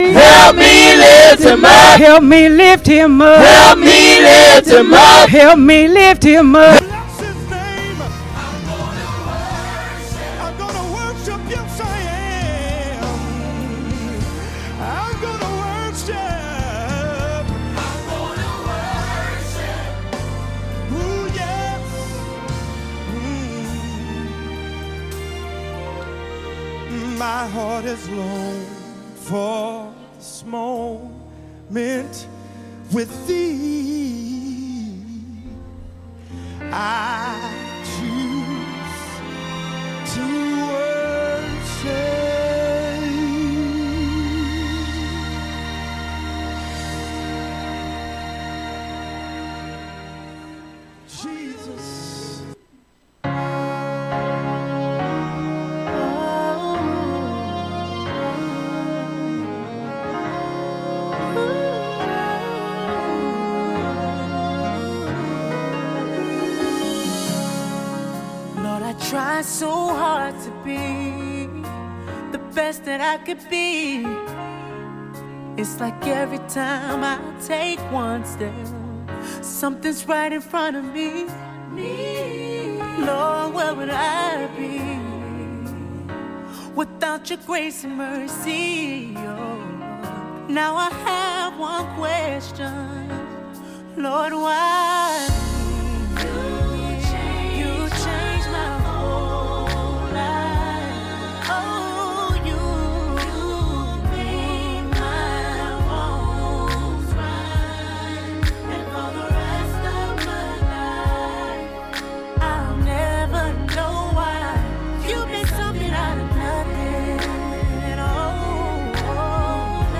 Sermons by Living Faith Christian Center OH